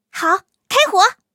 T43夜战攻击语音.OGG